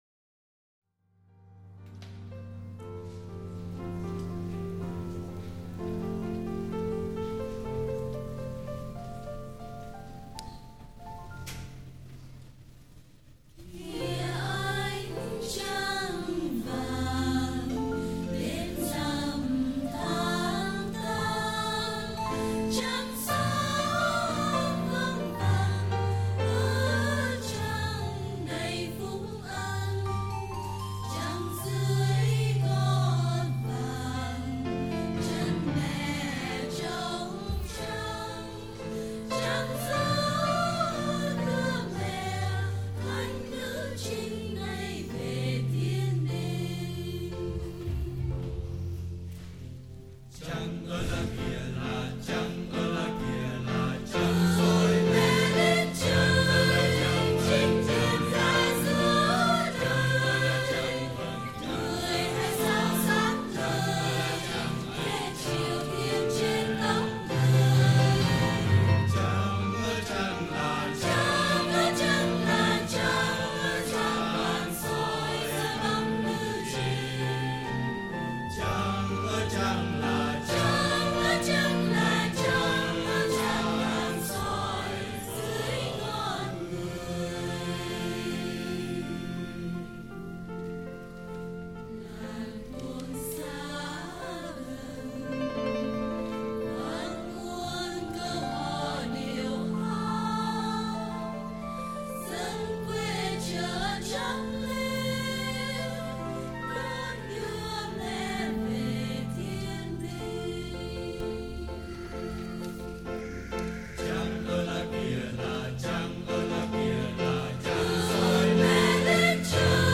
C. Thánh Nhạc, Ca Đoàn
Thân gửi đến các bạn nghe thử ca đoàn của mình hát bài Ơ Trăng nè ... không có giờ tập dợt nhiều, nhưng đây là kết quả của mọi cố gắng của chúng tôi ... hy vọng nghe rồi nếu không hay xin nương tay dùm ... cám ơn trước ...